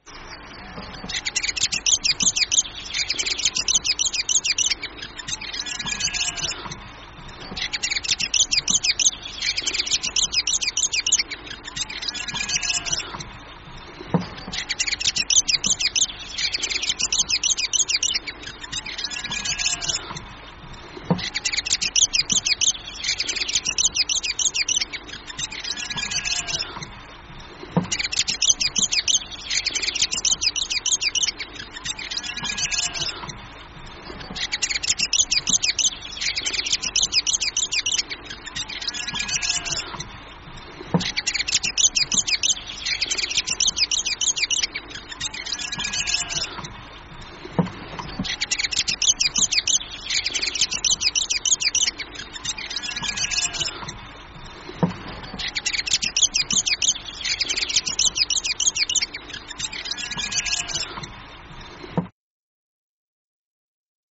NZ Fantail
Pīwakawaka Call
fantail.mp3